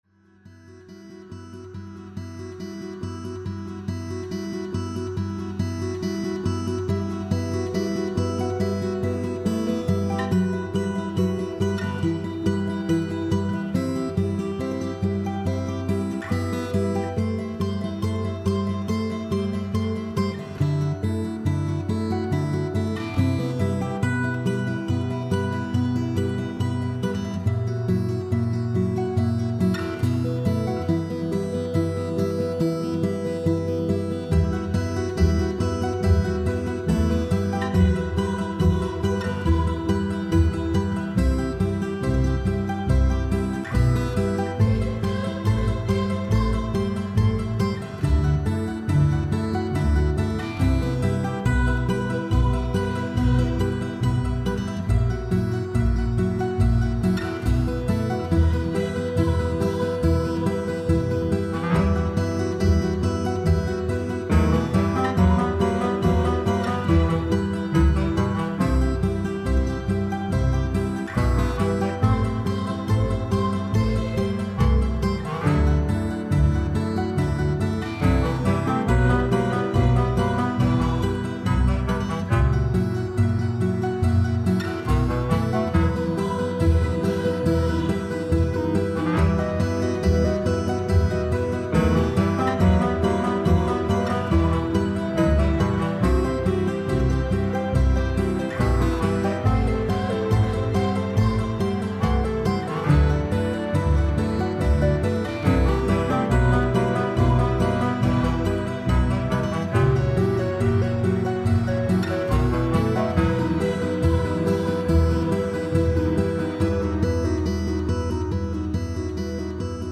folk - calme - melodieux - melancolique - guitare